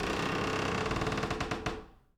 door_A_creak_07.wav